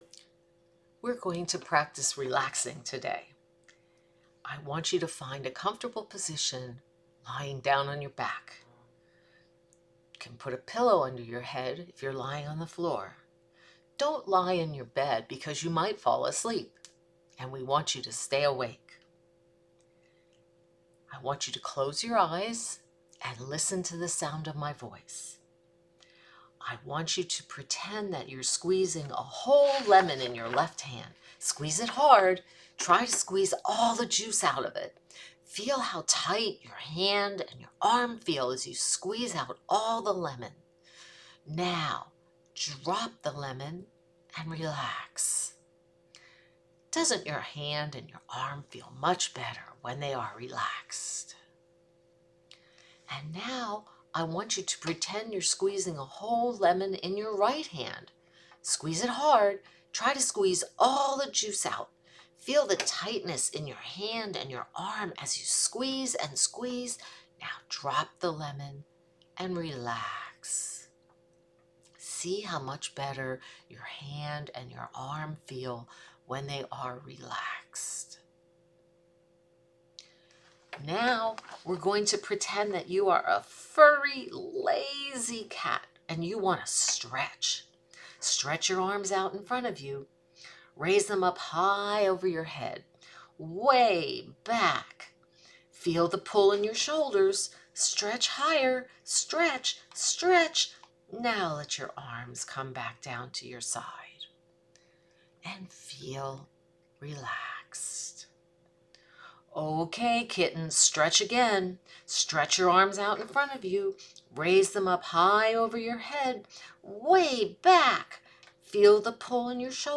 At this link below, you will find a relaxation recording that has been used successfully with children as young as three years old. The recording will guide the child through tensing and relaxing various muscle groups with the use of fun imagery.
Relaxation Recording
Relaxation-Training-for-Young-Children.mp3